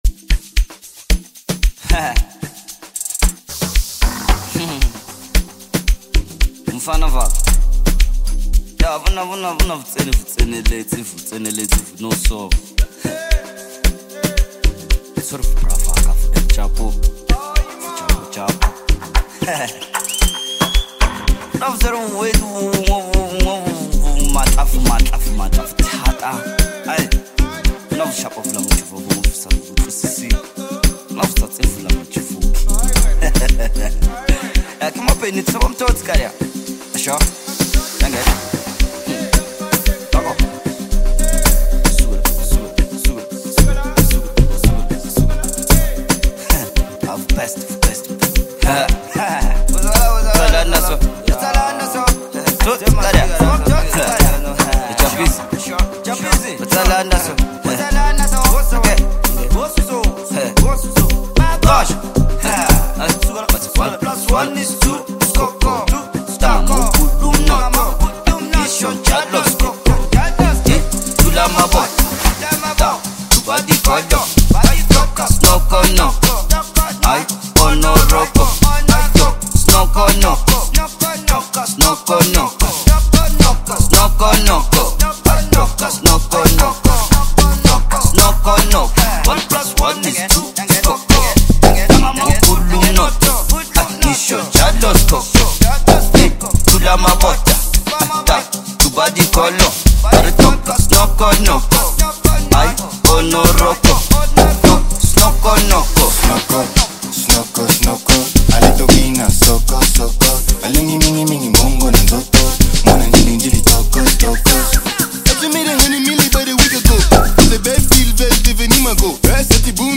high-energy Amapiano/Afro-dance single
Amapiano